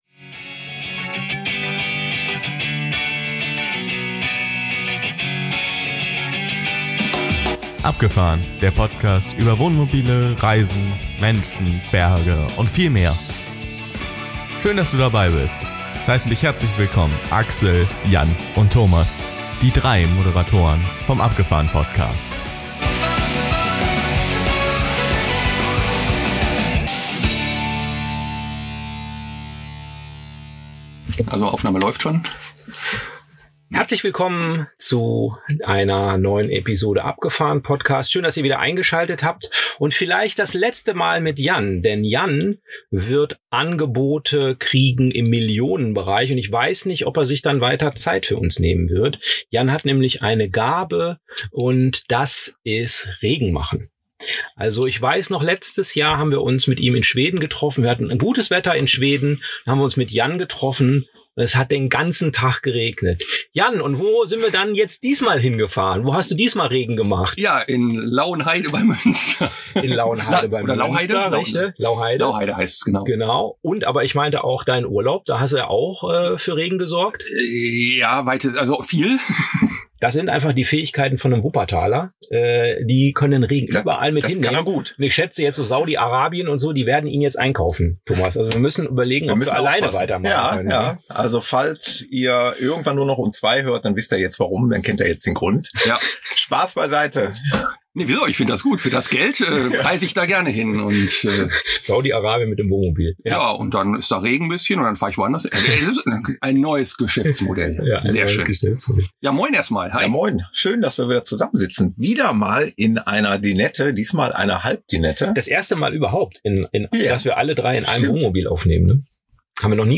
Wir sitzen gemeinsam in der Halbdinette und spielen mit vielen Zahlen die in dieser Reise vorkamen .